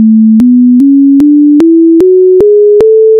Mathemusique-LU-GammeTemperee.wav